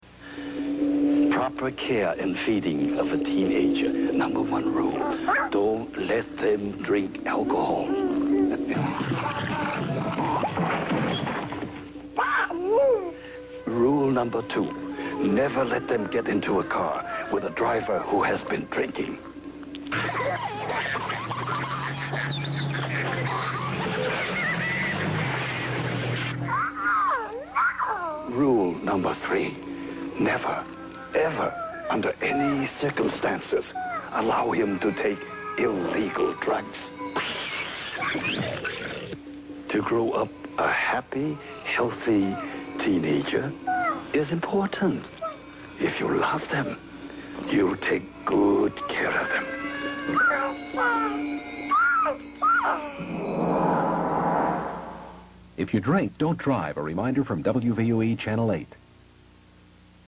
It even sounds like Howie Mandel is doing the Gizmo voice as well. Not bad, just wish we had a better quality copy of it!